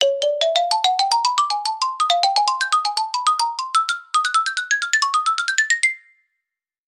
Звуки ксилофона
Лифт взмыл вверх